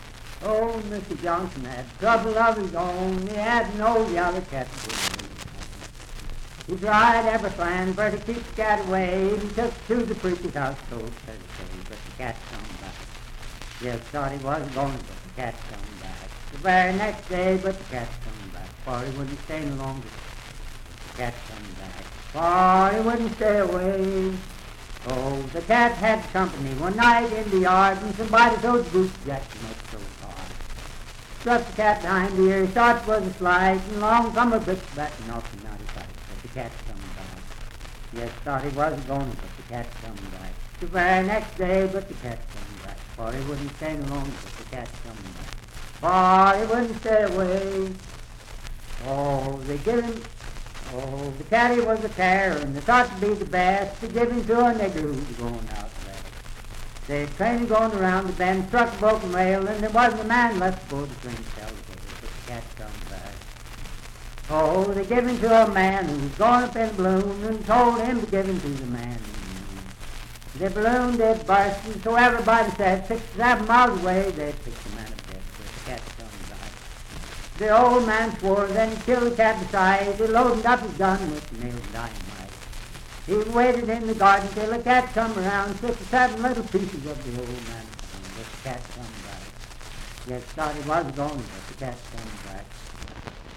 Unaccompanied vocal music
Verse-refrain 4(4) & R(4).
Voice (sung)